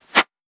weapon_bullet_flyby_04.wav